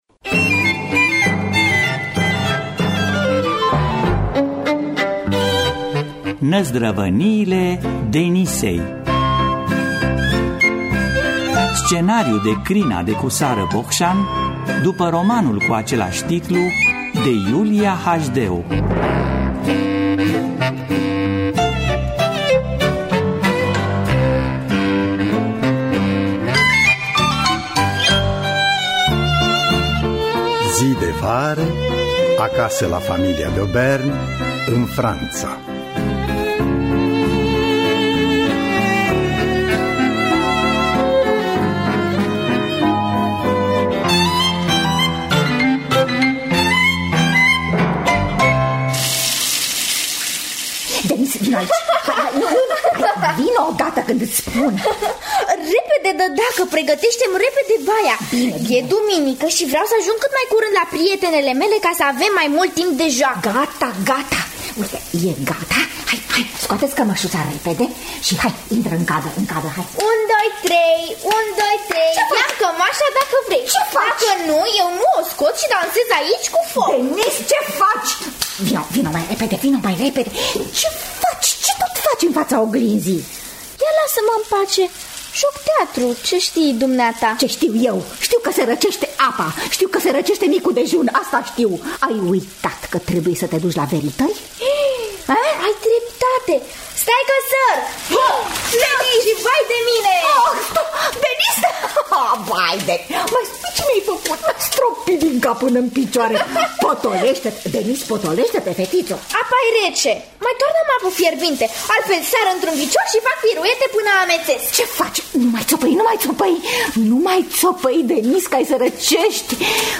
Năzdrăvaniile Denisei de Iulia Hașdeu – Teatru Radiofonic Online